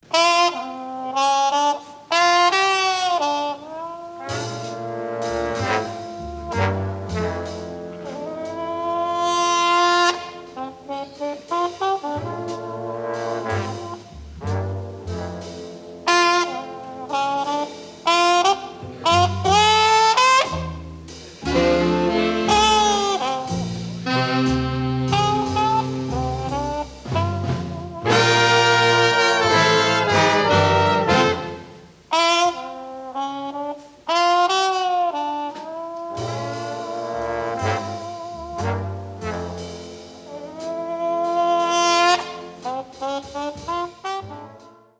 Helsinki 1963